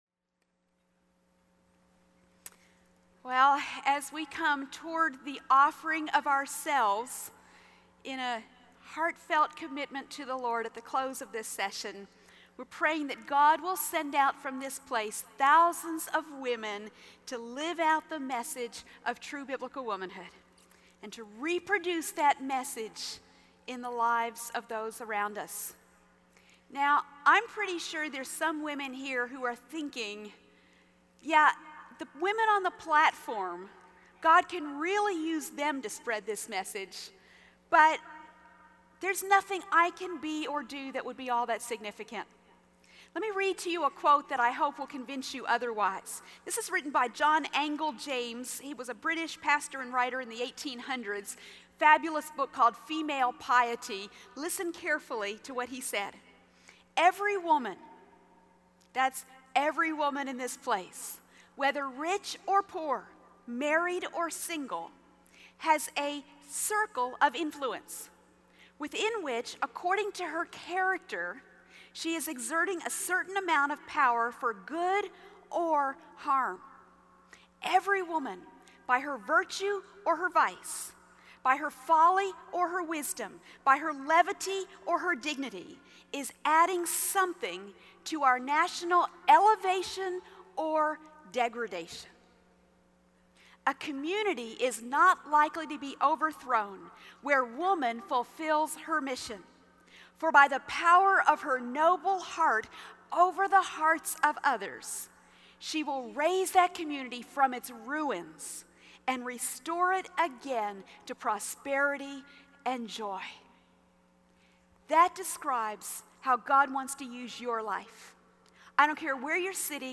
A True Woman Joins the Battle | True Woman '10 Chattanooga | Events | Revive Our Hearts